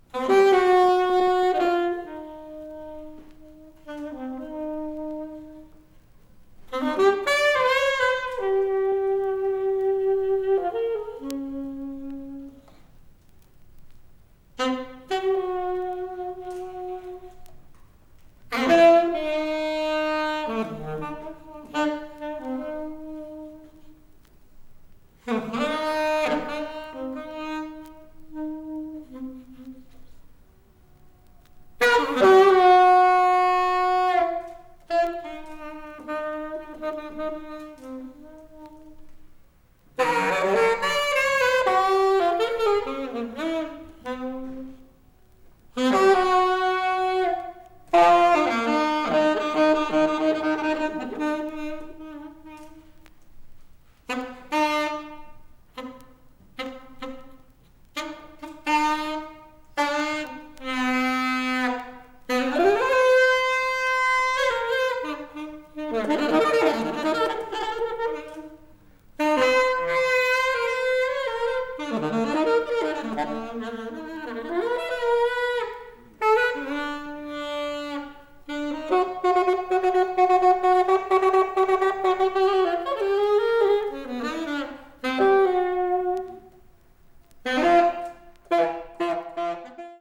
avant-jazz   free improvisation   free jazz   sax solo